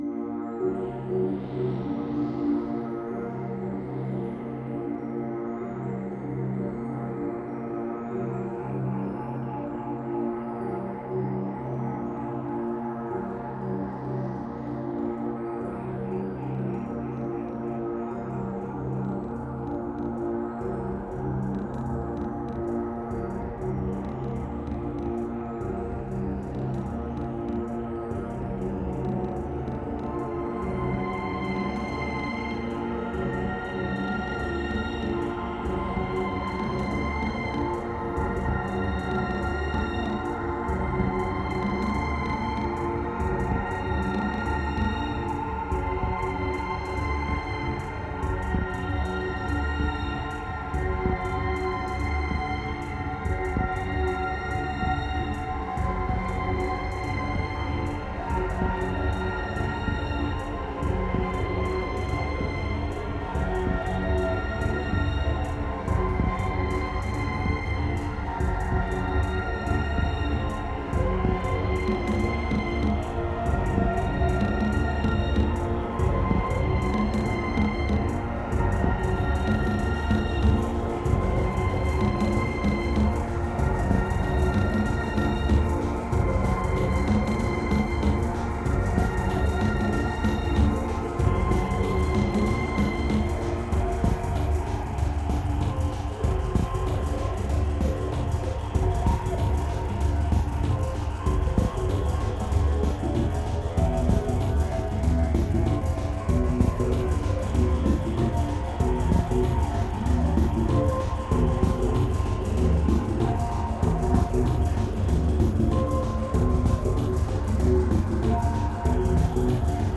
Pure remote desire, strong medication and raw electronica.
2322📈 - -31%🤔 - 96BPM🔊 - 2011-01-15📅 - -458🌟